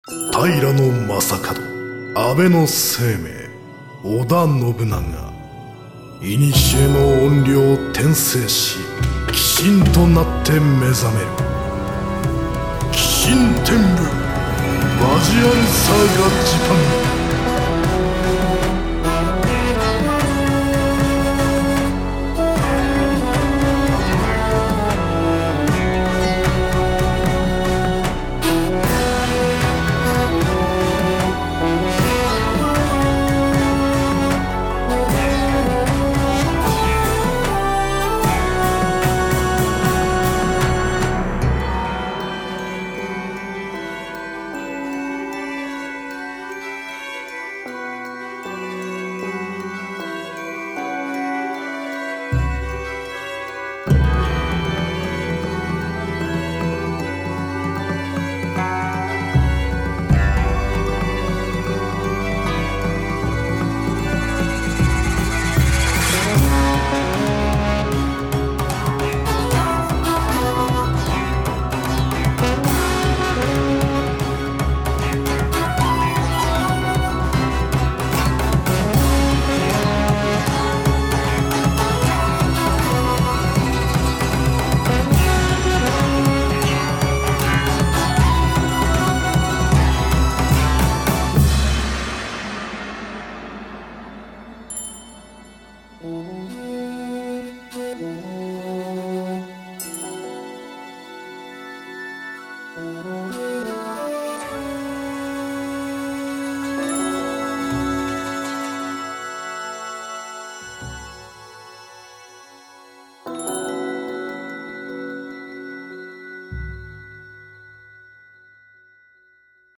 メインテーマ＆OPナレーション